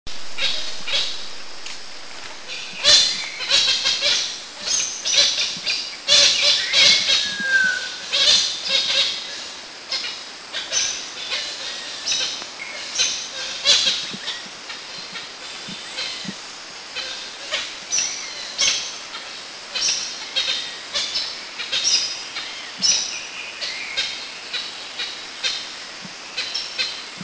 Samar Hornbill
Penelopides samarensis
SamarHornbill.mp3